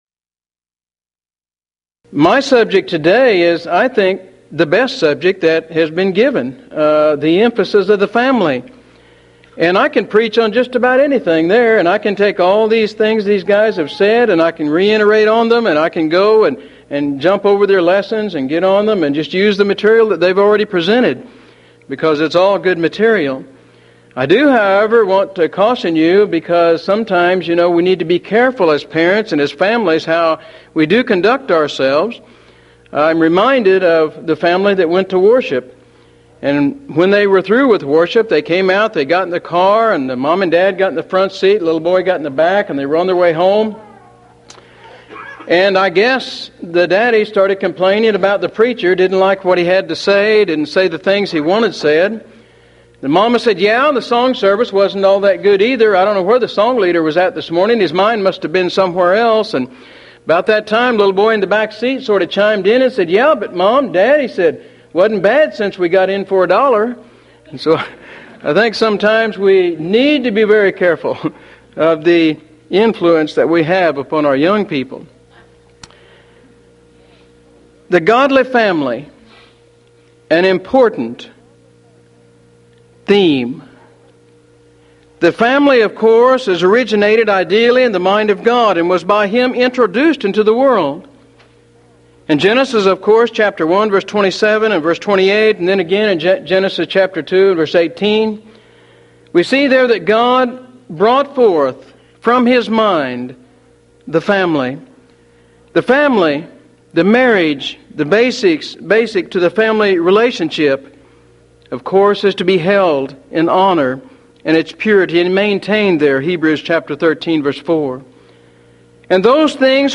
Event: 1993 Mid-West Lectures Theme/Title: The Christian Family
lecture